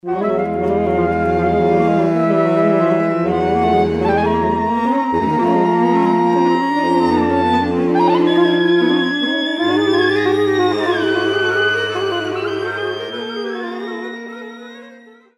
at Rose Recital Hall, University of Pennsylvania.